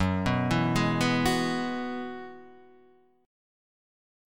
F# 9th Suspended 4th